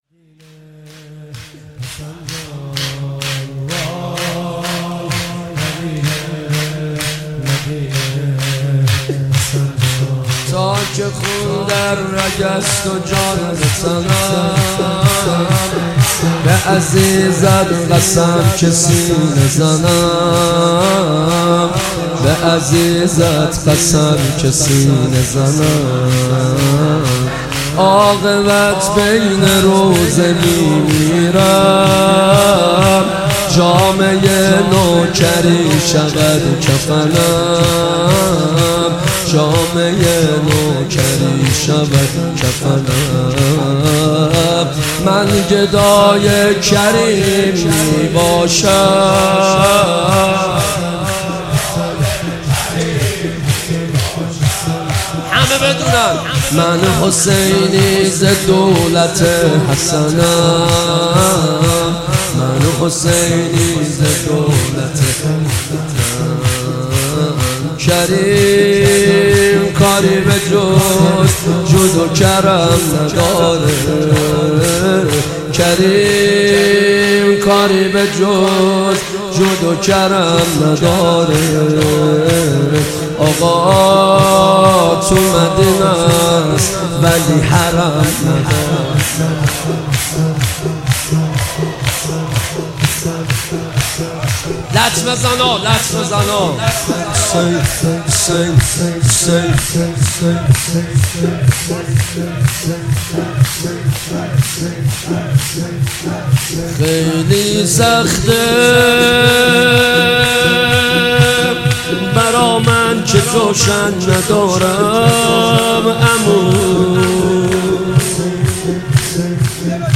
نوحه های بیشتر